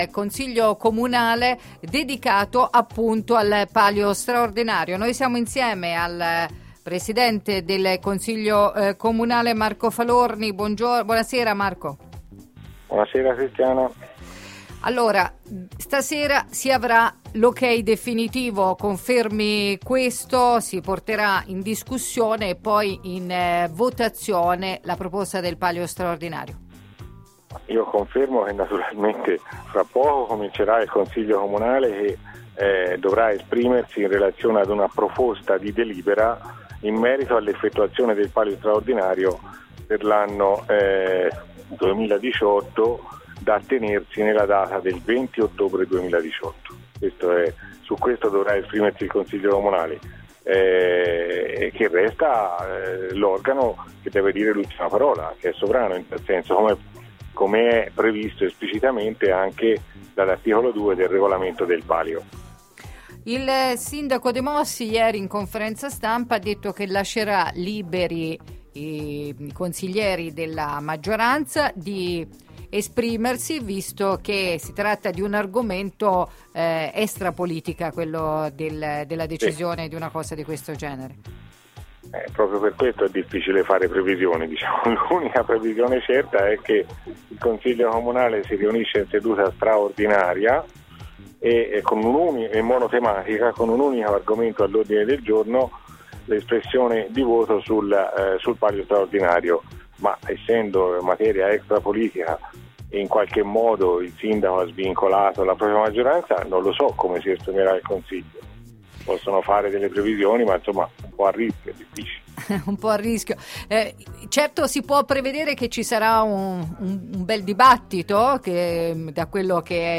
Interviste